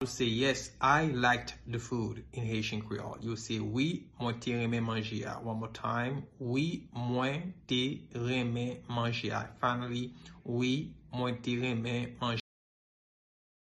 Pronunciation:
Yes-I-liked-the-food-in-Haitian-Creole-Wi-mwen-te-renmen-manje-a-pronunciation.mp3